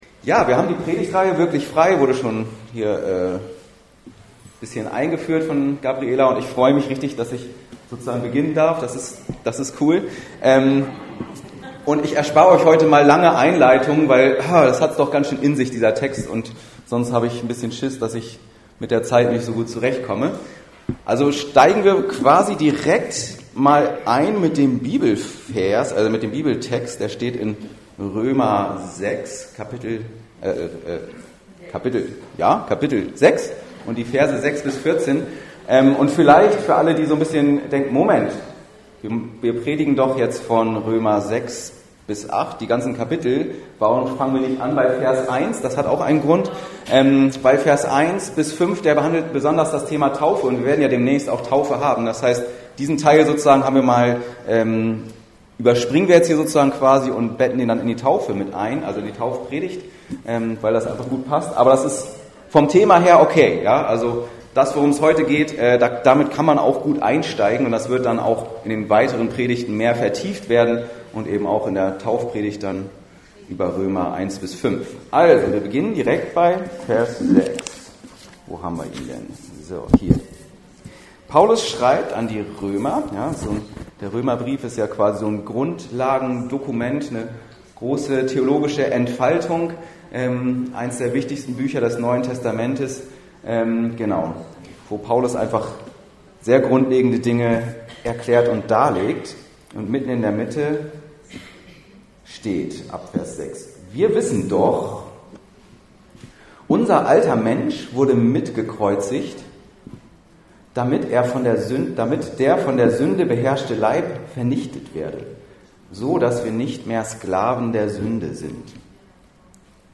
Wirklich Frei…vom alten Chef ~ Anskar-Kirche Hamburg- Predigten Podcast